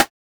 Snare (Drip).wav